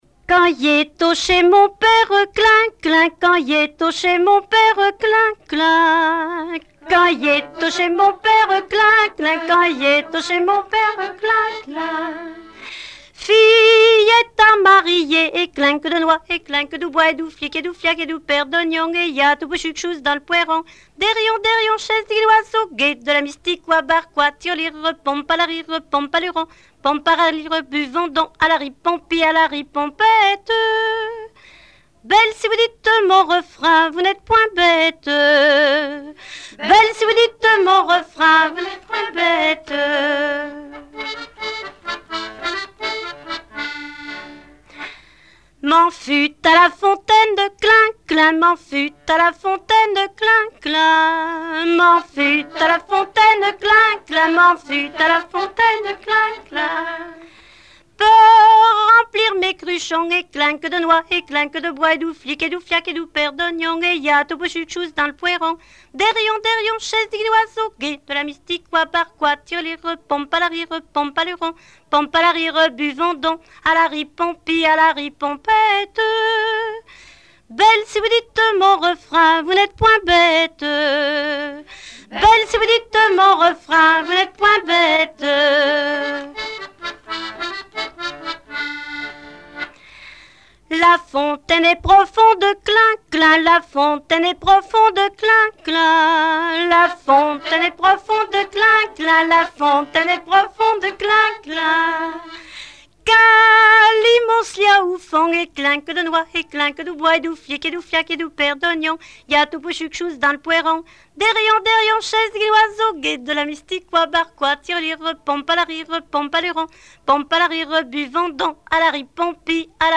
Genre laisse
Chansons traditionnelles
Pièce musicale inédite